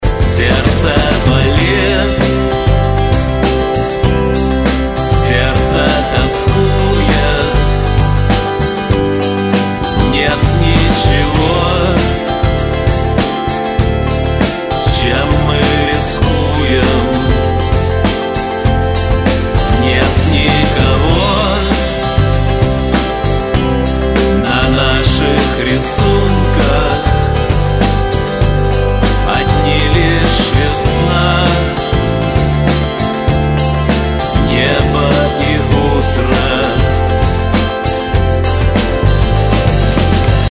Рок-музыка